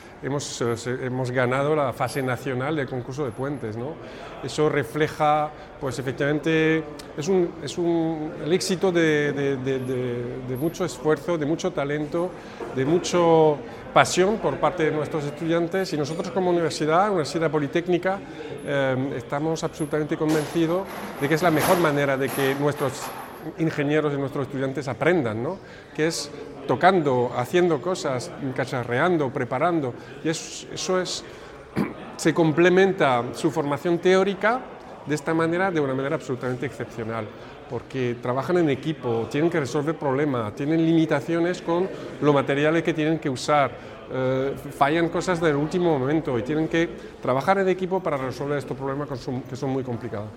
Audio: Declaraciones de la alcaldesa, Noelia Arroyo, visita a los estudiantes UPCT ganadores del Concurso de Puentes (MP3 - 689,36 KB)